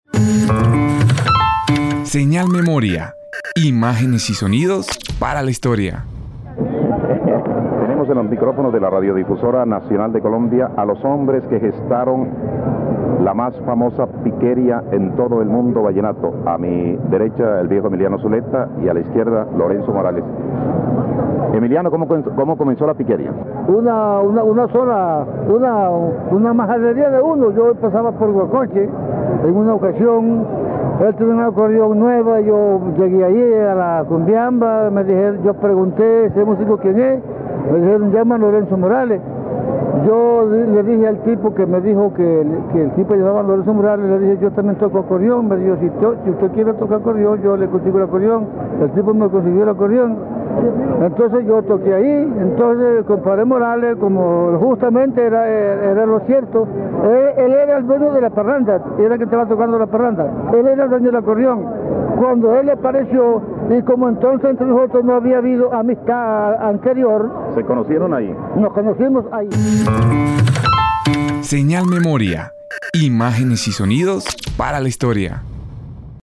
Entrevista
Programa Informativo